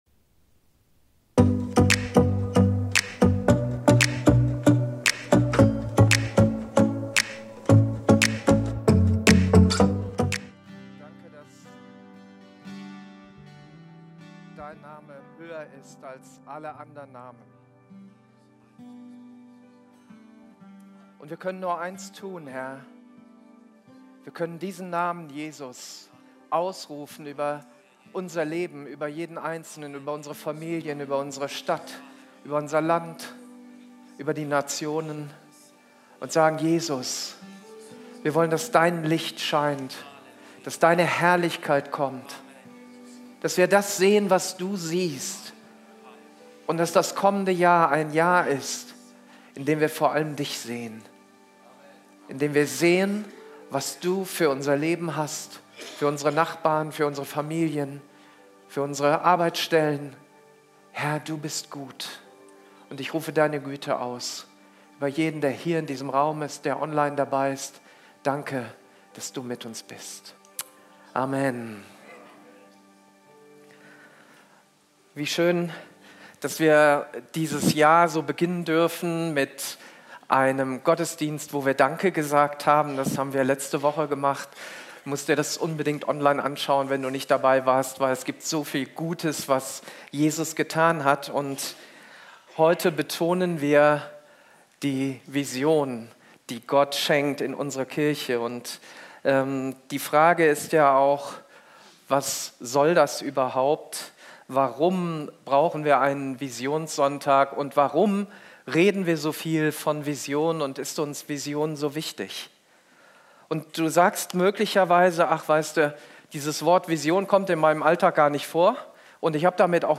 Live-Gottesdienst aus der Life Kirche Langenfeld.
Kategorie: Sonntaggottesdienst